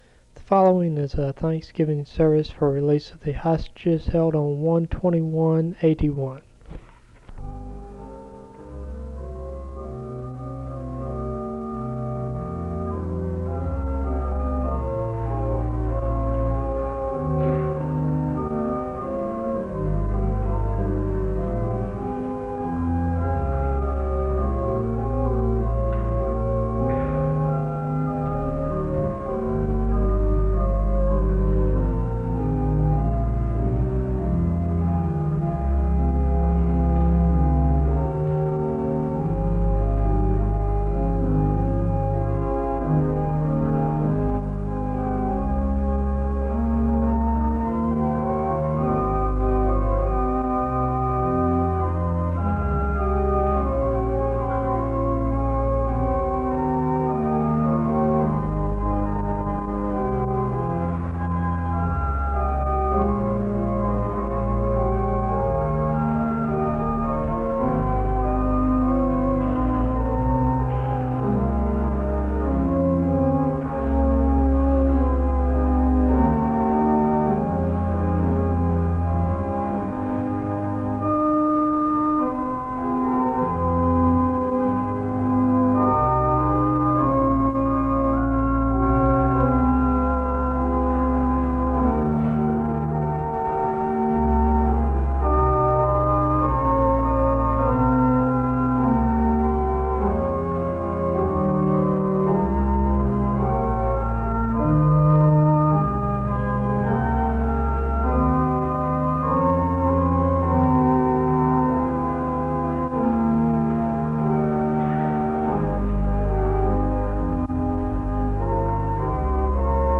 The service begins with organ music (00:00-04:36).
The audience is led in a responsive prayer (18:22-19:25). A speaker gives a biblical approach to dealing with the sufferings that happened with the hostage crisis (19:26-24:06).
Responsive worship